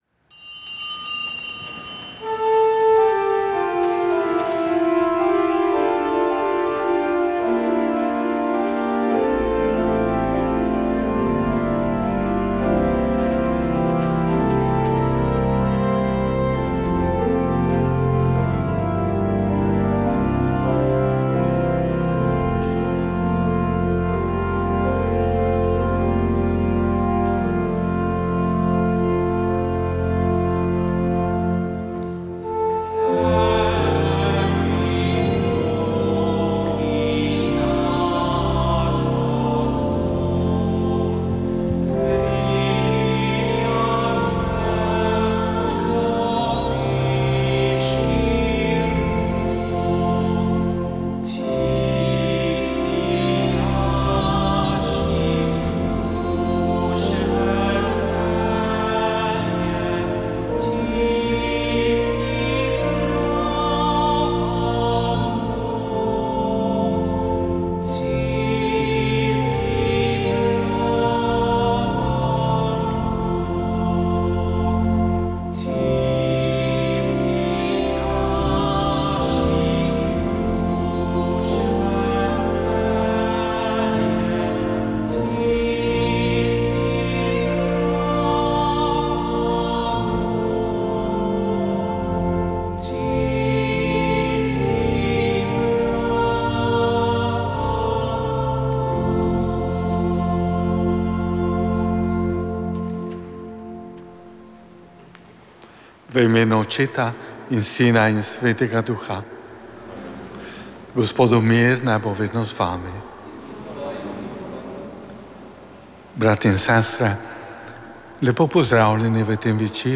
Sveta maša
Sveta maša iz podružnične cerkve sv. Janeza Krstnika v Bohinju dne 30.07.